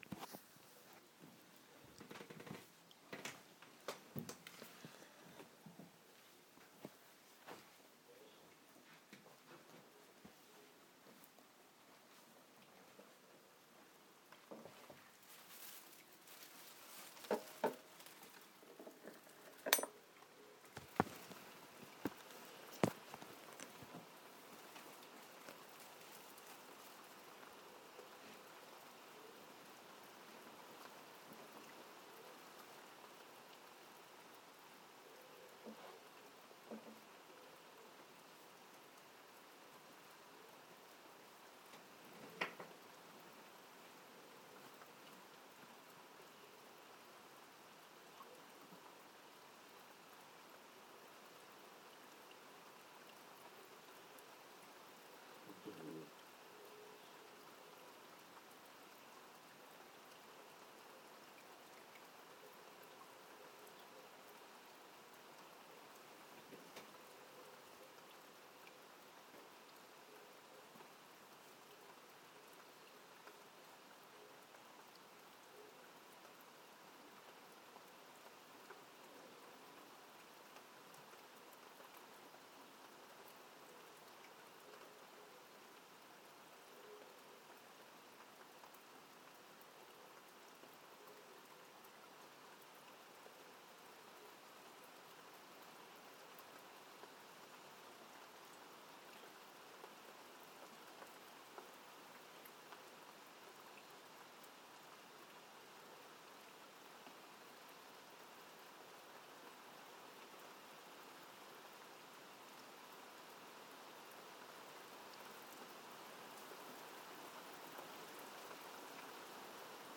Beautiful nighttime rain